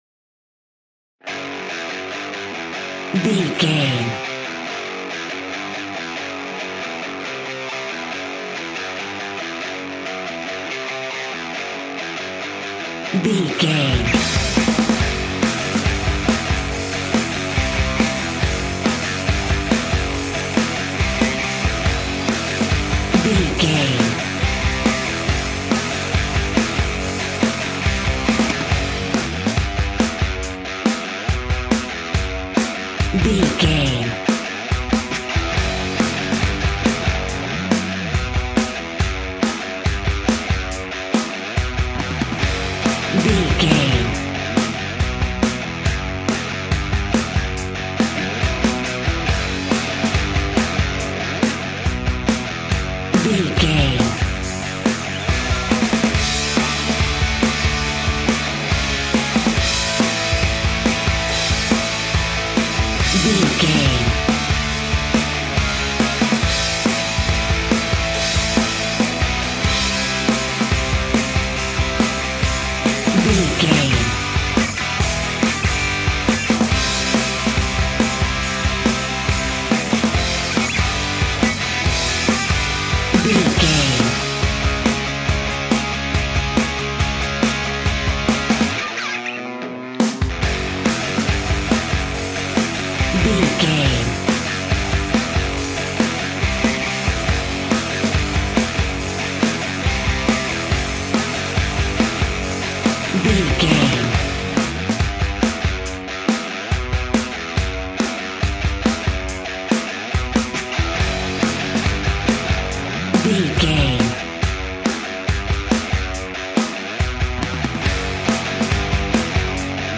Epic / Action
Aeolian/Minor
F#
hard rock
heavy rock
guitars
dirty rock
Heavy Metal Guitars
Metal Drums
Heavy Bass Guitars